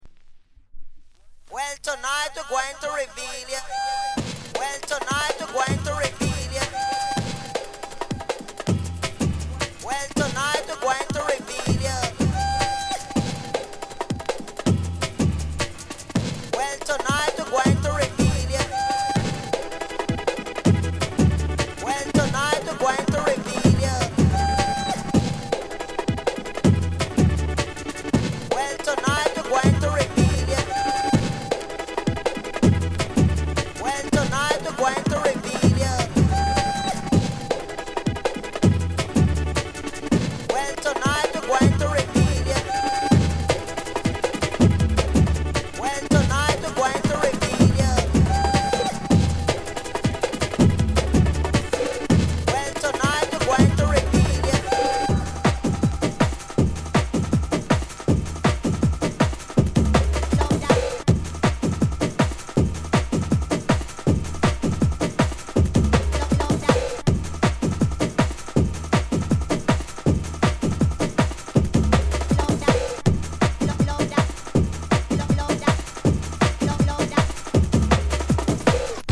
当前位置 > 首页 >音乐 >唱片 >世界音乐 >雷鬼